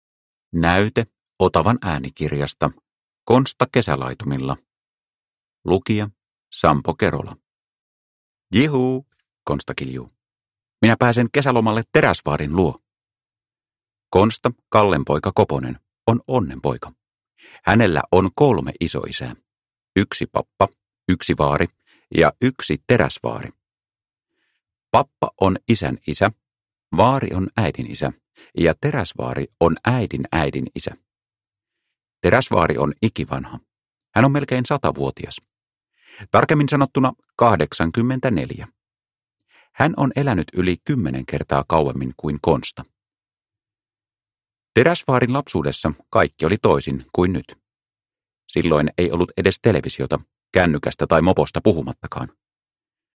Konsta kesälaitumilla – Ljudbok – Laddas ner